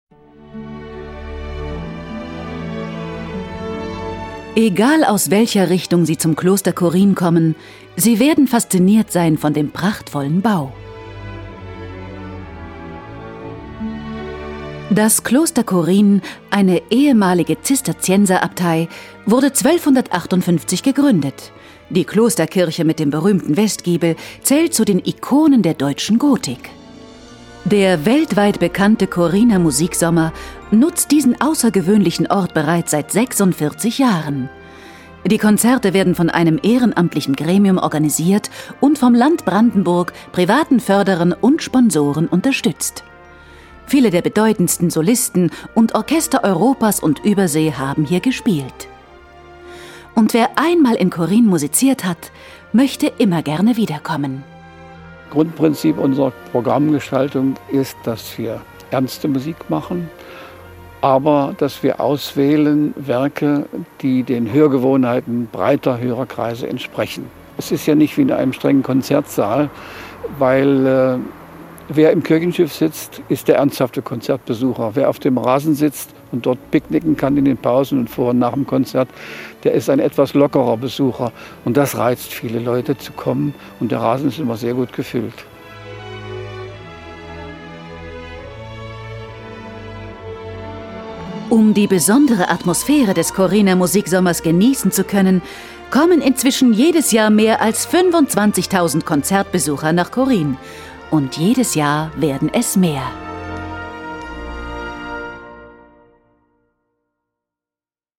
Sprecherin für Hörbuch, Synchron, Dokumentarfilm, Feature
Imagefilm 1